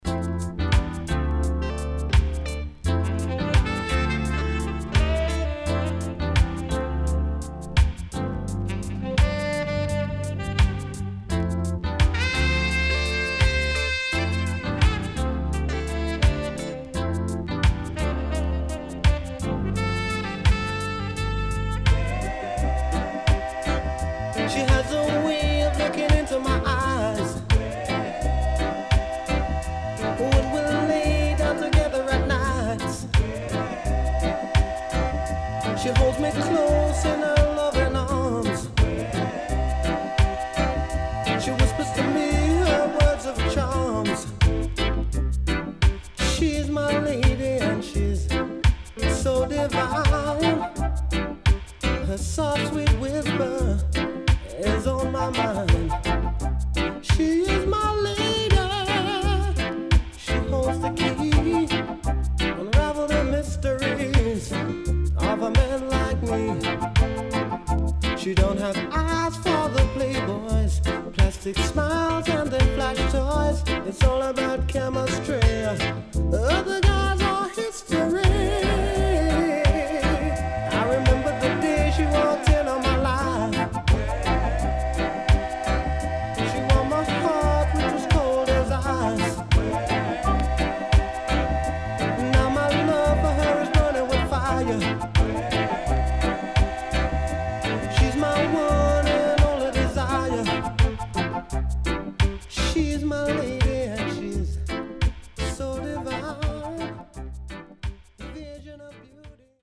Sweet Vocal & Inst.W-Side Good